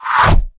Arrow 6.wav